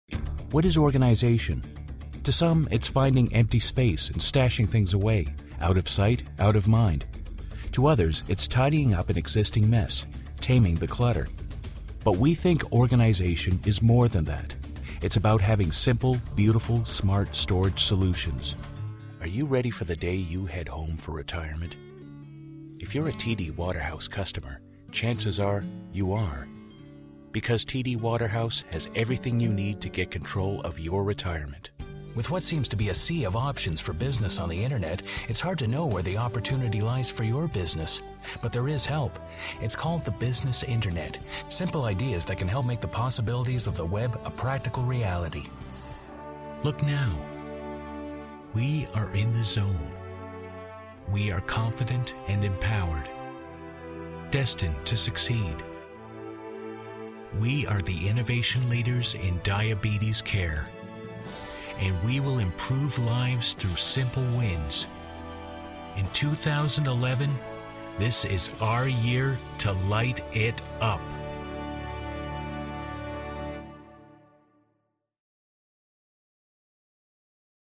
-male non-union voice talent -resonant mid-range to bass -clear, warm and friendly -good dramatic range & comedic timing -ideally suited for narration, corporate, commercial work and audio books
Sprechprobe: Industrie (Muttersprache):